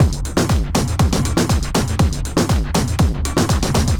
Gear Up (Drums) 120BPM.wav